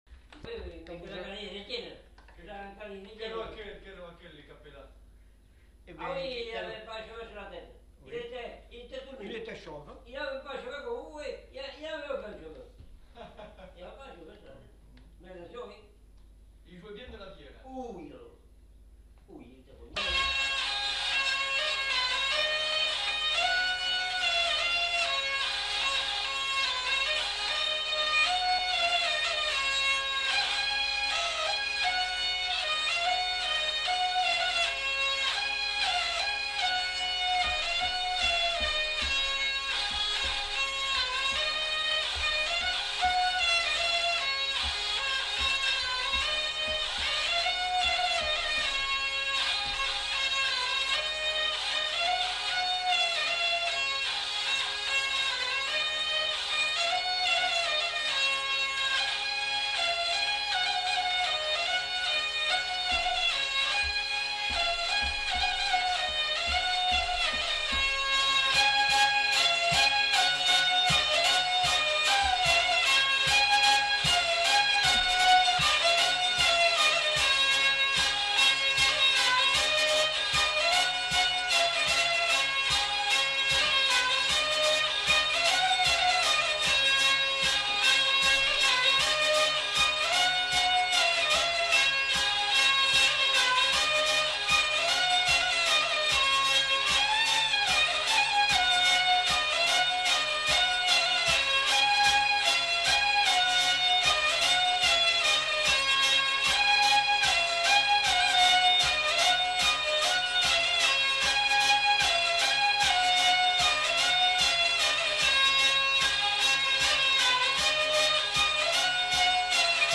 Lieu : Vielle-Soubiran
Genre : morceau instrumental
Instrument de musique : vielle à roue
Danse : mazurka